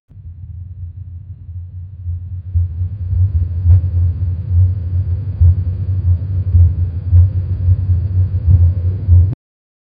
mec, c'est too much, vers la fin je voudrai que le son soit lent répétitif, indiquant que le vaisseau est en cruise et en déplacement stable, mais garde le coté démarage au début